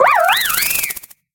Cri de Manternel dans Pokémon X et Y.